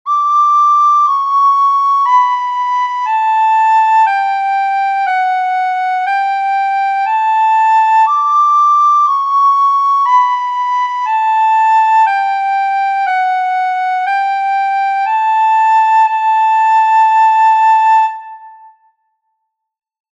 This is a three-voice canon, Pachelbel’s Canon in D major (with F# and C#). The score includes three different instruments (violin, recorder, guitar) so that the three voices can be distinguished.
Second voice.
canon_a_tres_voces_voz2.mp3